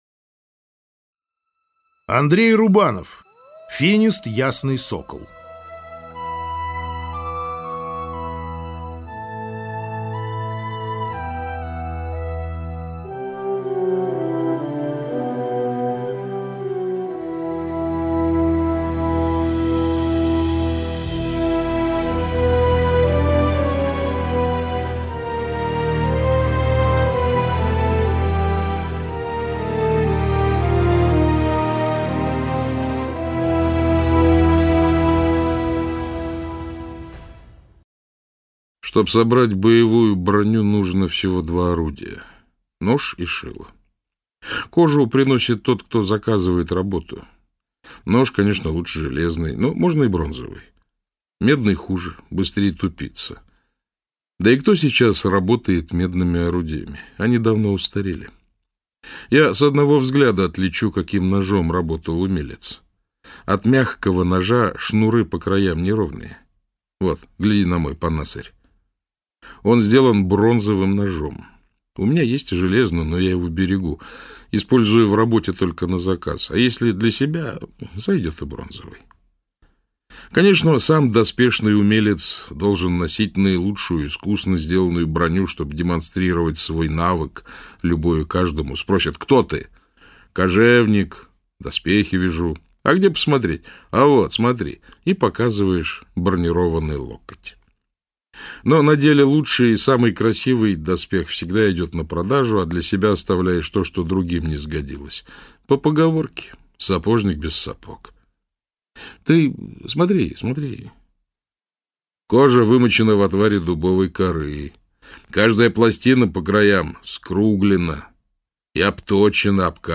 Аудиокнига Финист – ясный сокол | Библиотека аудиокниг